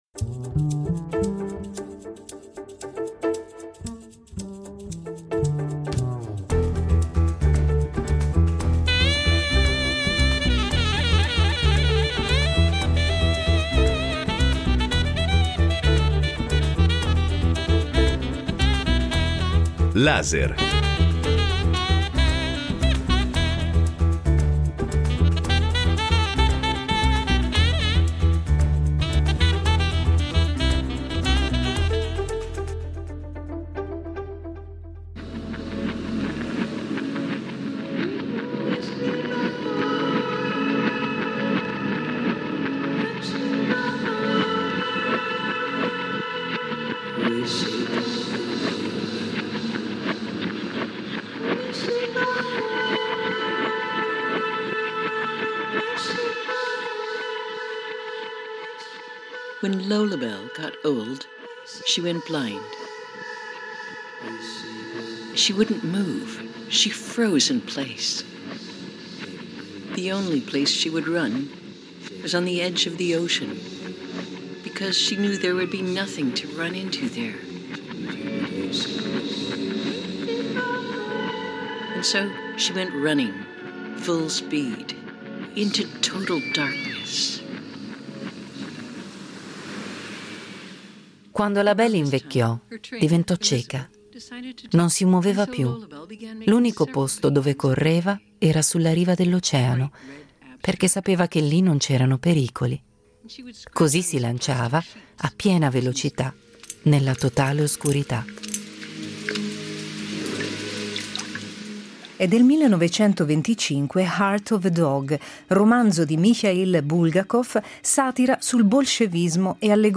Oltre alla breve intervista